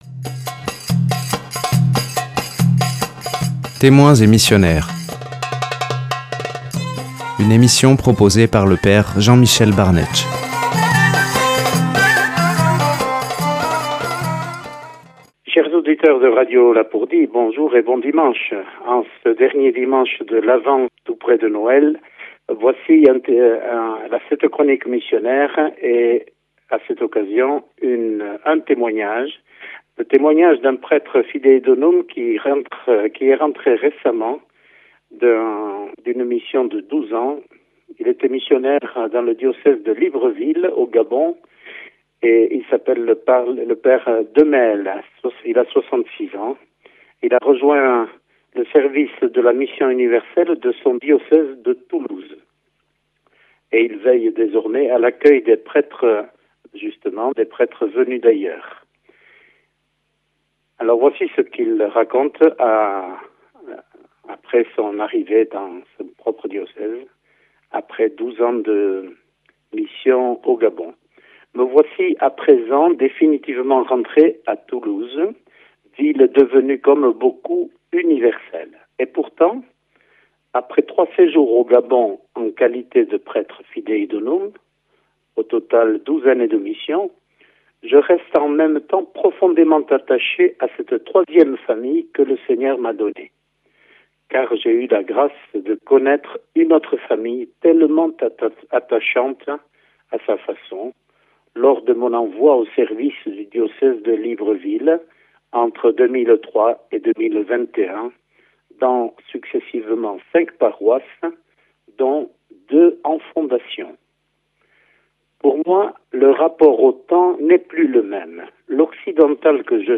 Une émission présentée par
Présentateur(trice)